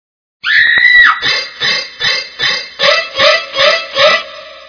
Scream with Psycho.wav